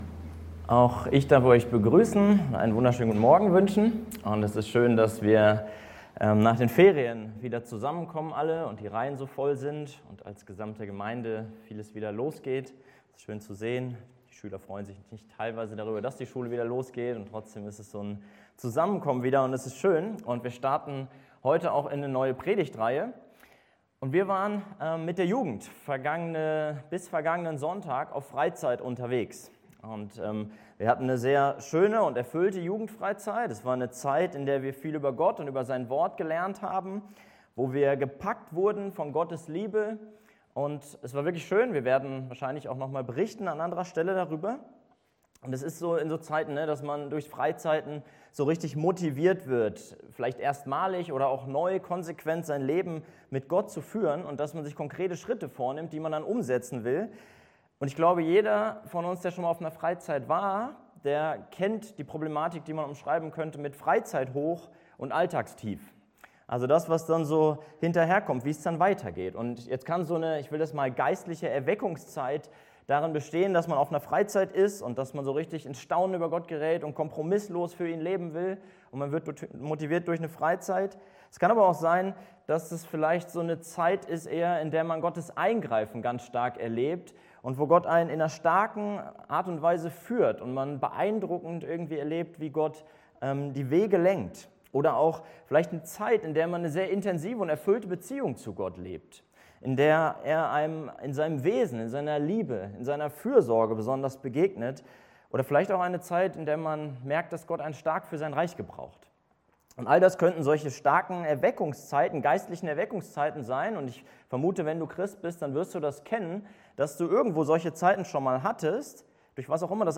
In der heutigen Predigt ging es um folgende Punkte: Gottes Liebeserklärung (Verse1-2a ) Gottes Liebesbeweis (Verse 2b-5) HauskreisLeitfaden Aufnahme (MP3) 52 MB Zurück Open Doors Weiter Schrott für Gott?